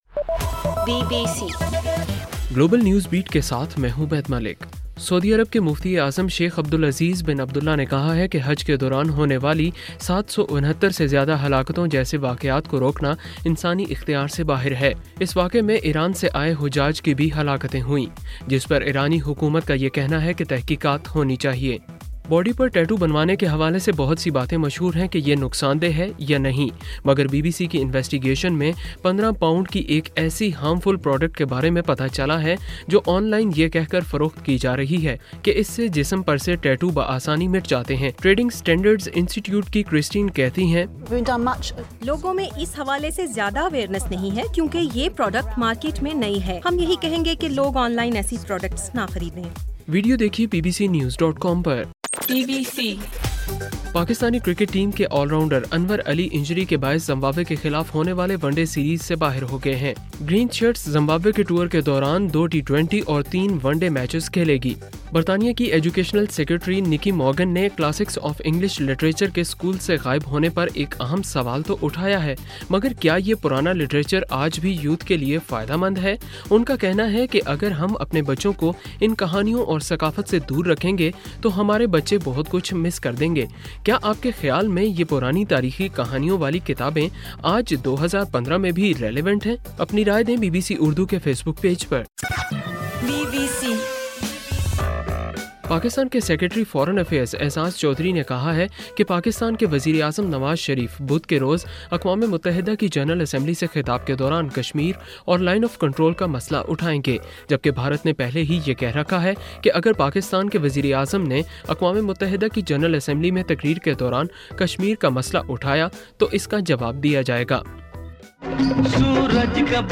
ستمبر 26: رات 8 بجے کا گلوبل نیوز بیٹ بُلیٹن